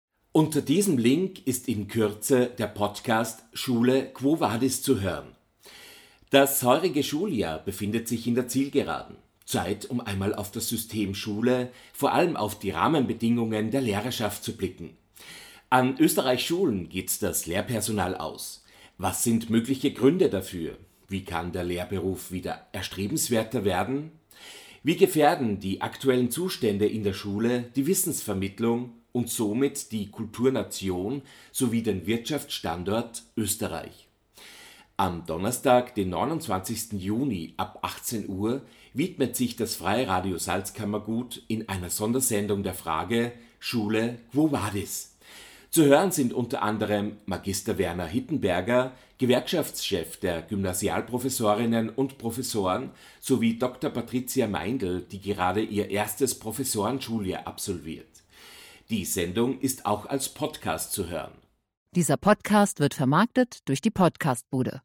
Am Donnerstag, den 29. Juni, ab 18h widmet sich das Freie Radio Salzkammergut in einer Sondersendung der Frage „Schule – quo vadis?“.